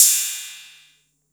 TR 808 Cymbal 01.wav